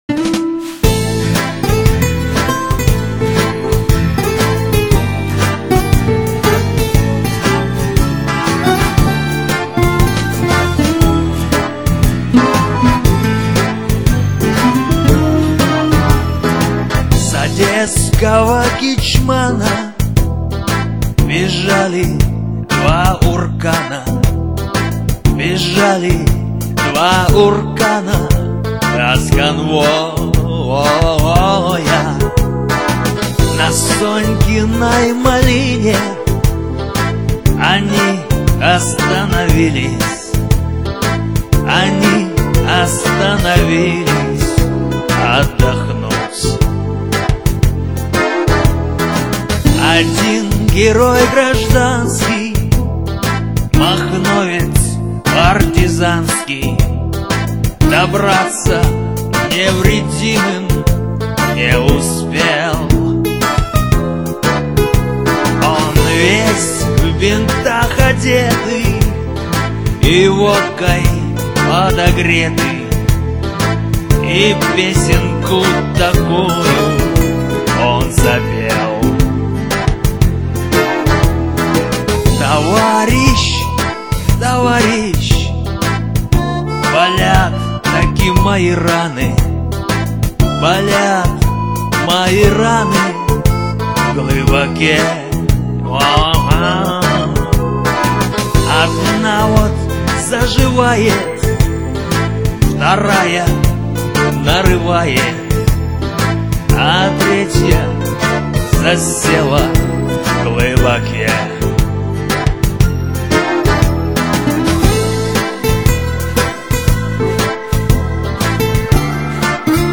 Архив ресторанной музыки